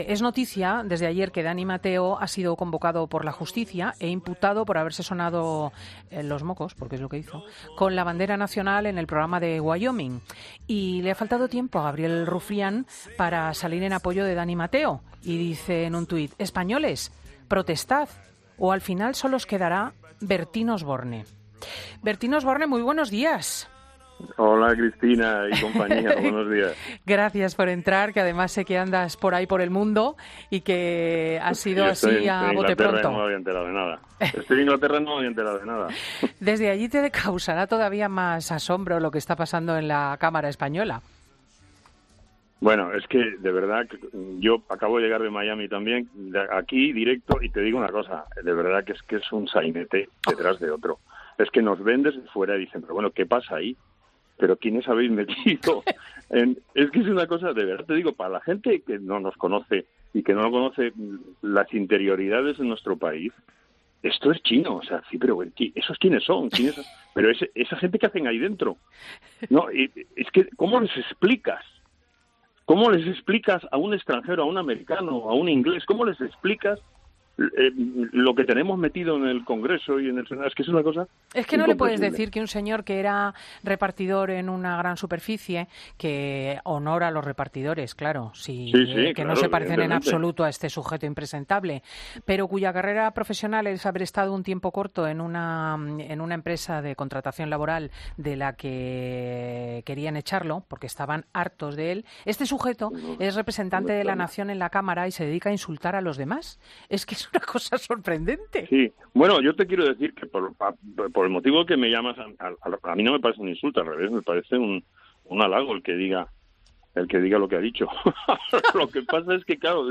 Bertín Osborne dice en Fin de semana Cope que Gabriel Rufián "es un imbécil con carné del que puedes esperar cualquier cosa"
Así ha respondido Bertín Osborne en el programa Fin de semana de Cope a la provocación que el diputado de ERC le hacía en Twitter.